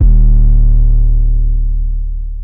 Movie808_YC.wav